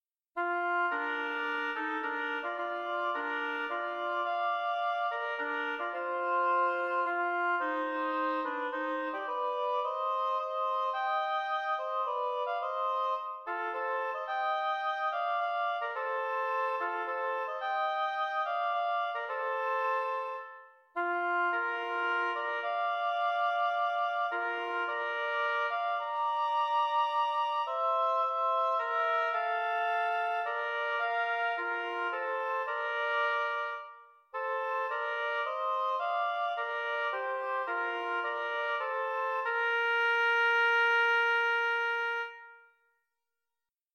oboe music